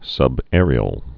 (sŭb-ârē-əl)